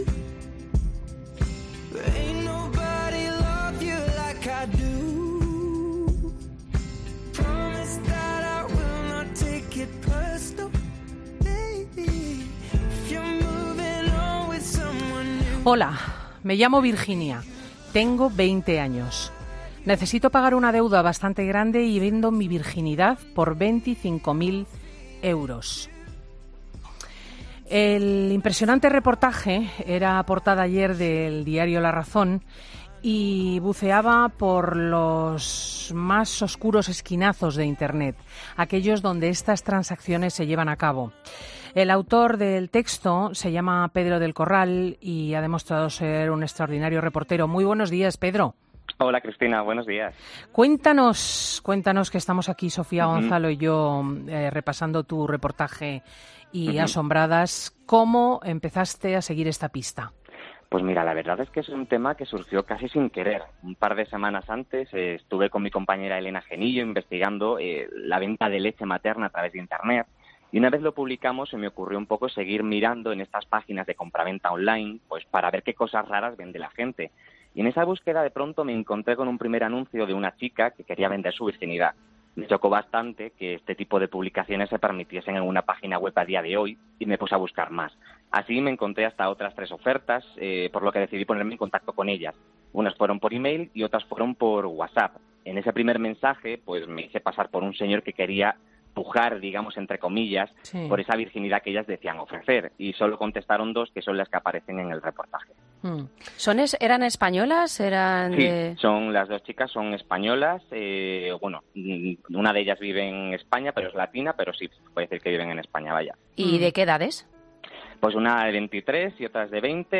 Escucha ahora la entrevista de Cristina L. Schlichting', emitida el 9 de septiembre, en FIN DE SEMANA .